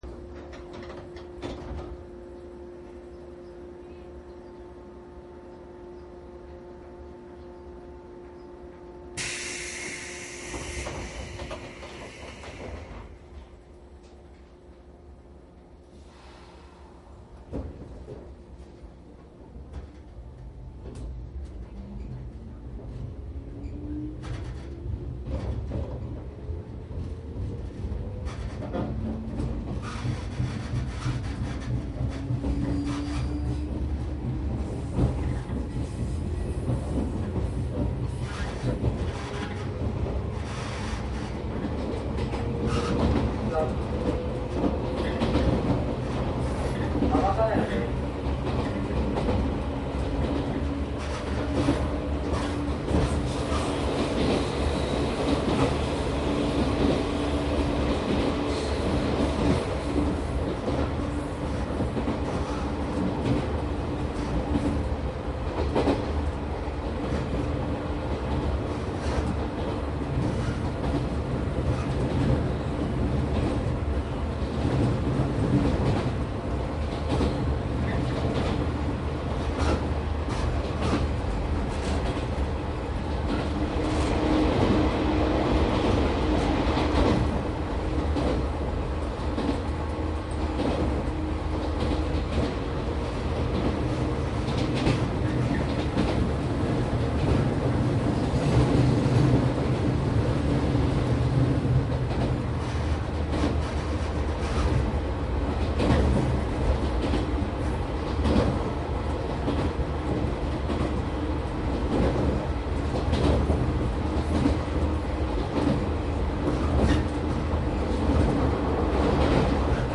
内容は♪JR内房線 113系走行音の収録です。
マスター音源はデジタル44.1kHz16ビット（マイクＥＣＭ959）で、これを編集ソフトでＣＤに焼いたものです。